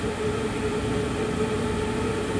vent.wav